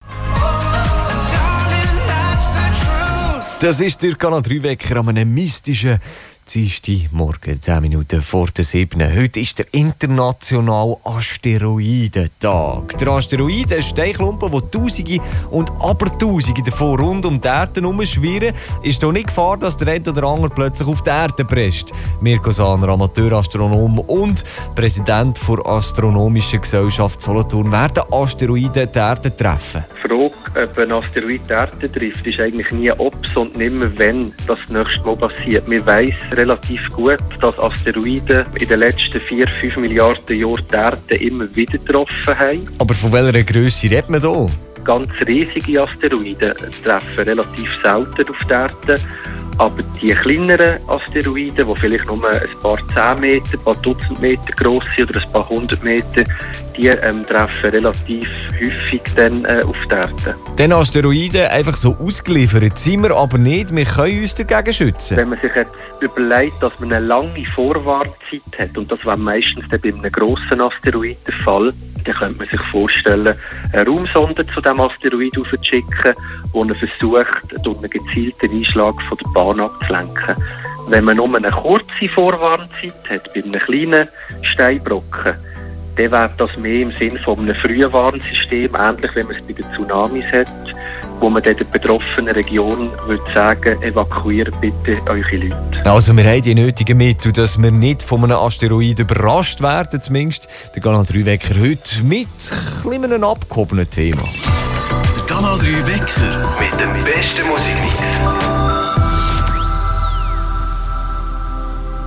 Interview zum Asteriodentag Ende Juni 2020 Canal 3 Teil #1